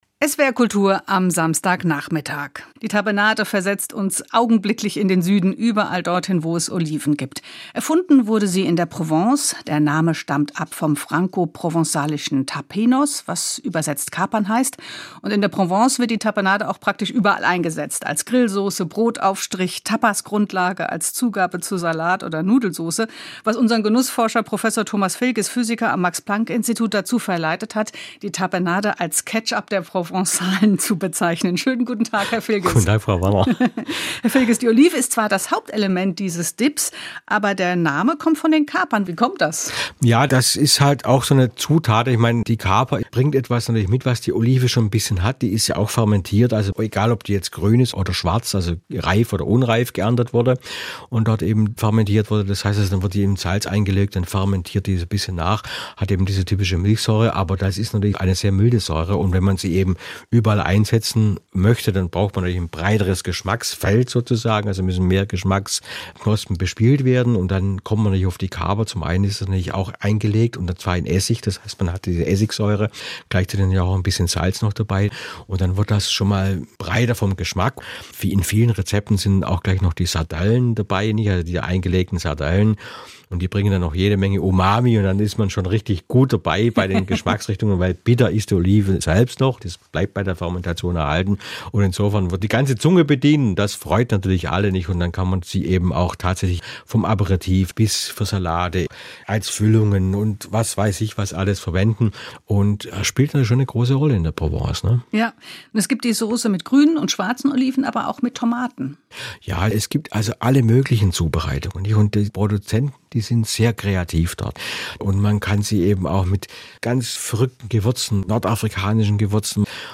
Das Interview führte
Interview mit